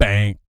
BS BANG 05-L.wav